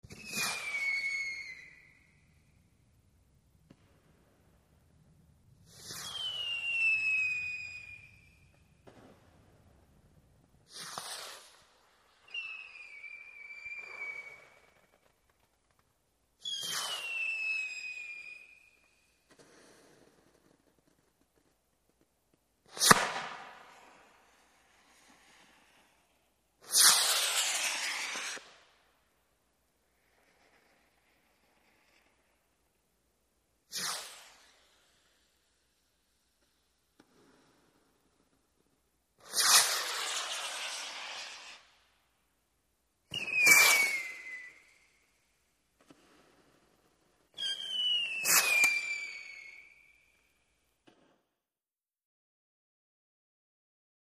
Bottle Rocket Launches, W Whistle And Explosions, From Various Distances.